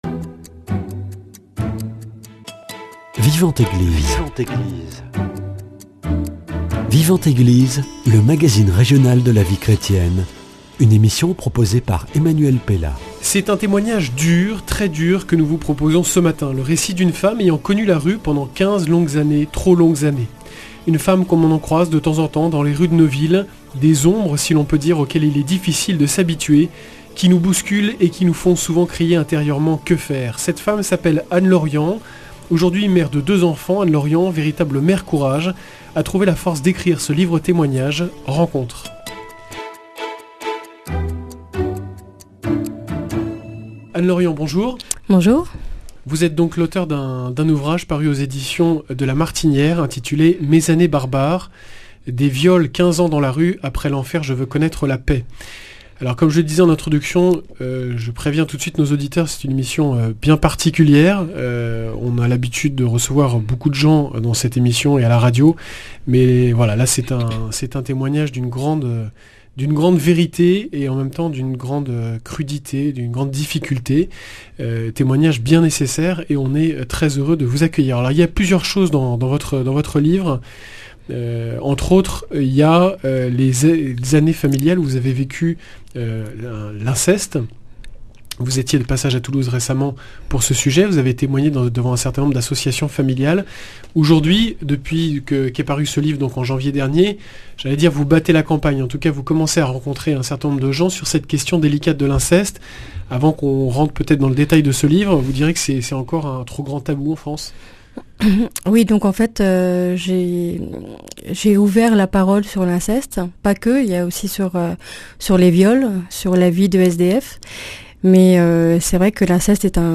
C’est un témoignage dur, très dur, que nous vous proposons ce matin.